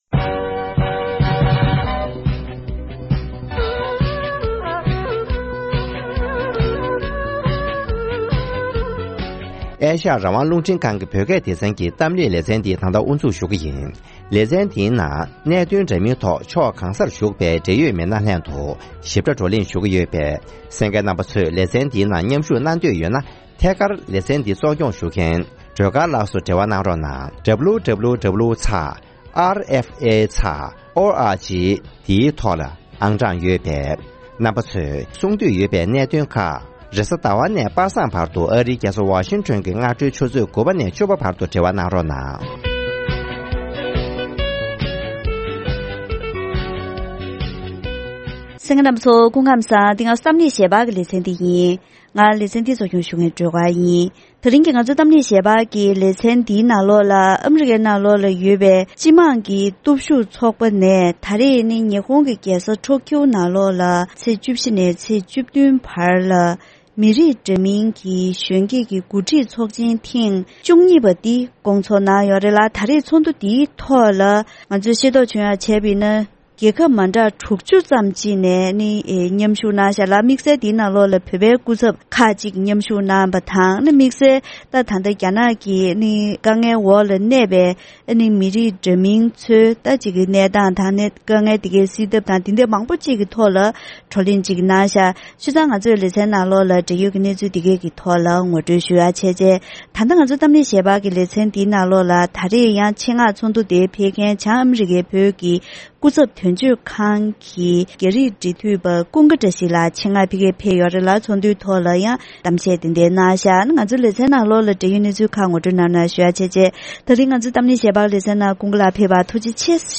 ༄༅། །ཐེངས་འདིའི་གཏམ་གླེང་ལེ་ཚན་ནང་།